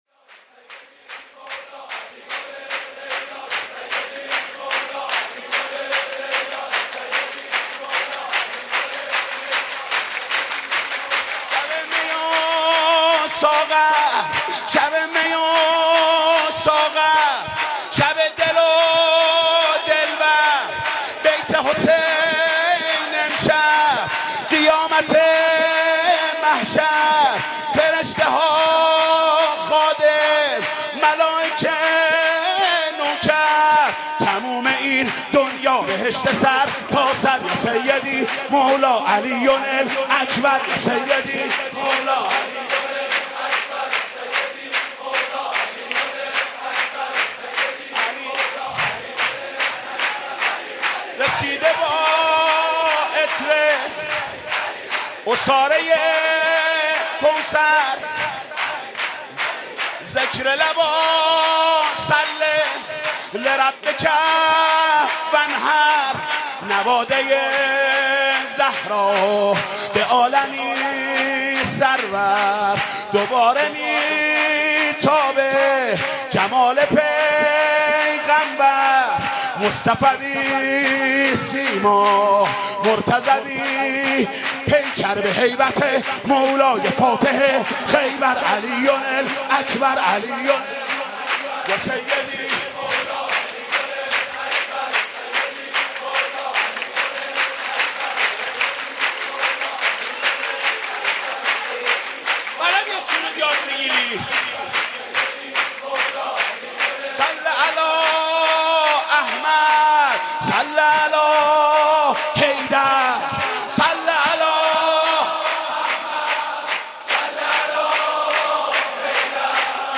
مولودی به مناسبت ولادت حضرت علی اکبر(ع)